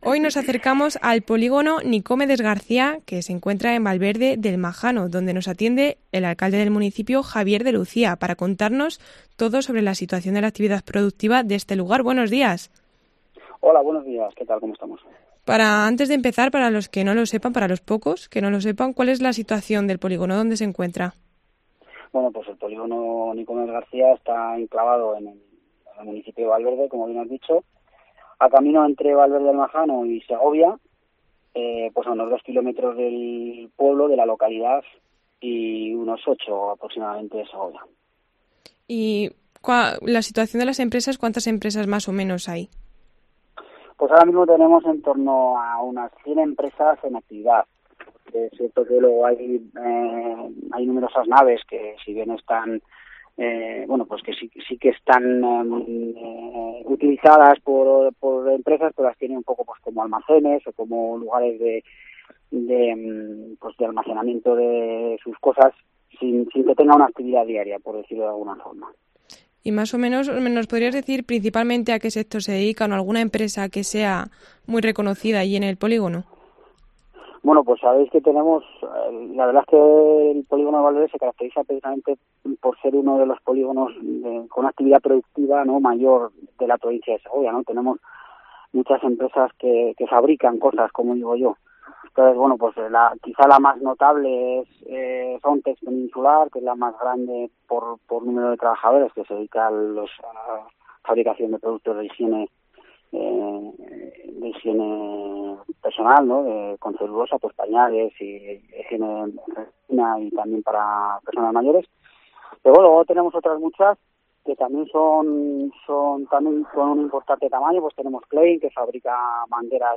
Entrevista al alcalde del municipio, Javier Lucía